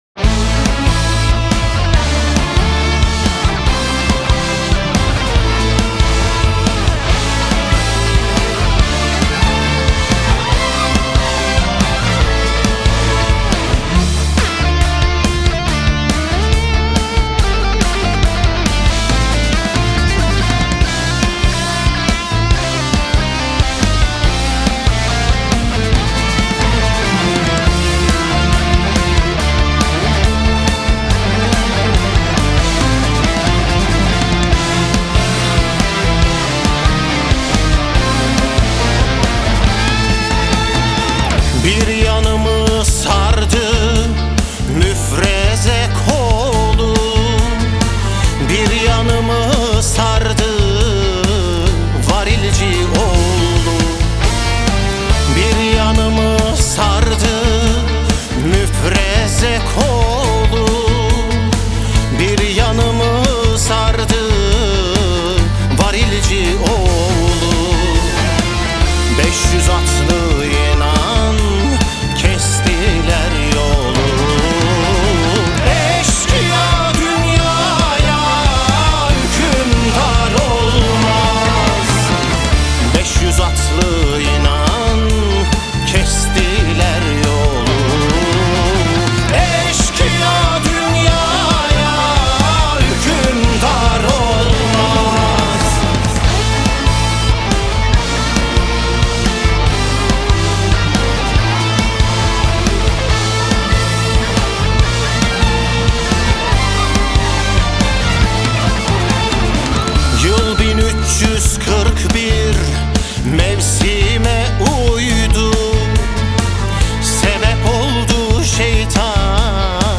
Трек размещён в разделе Турецкая музыка / Рок.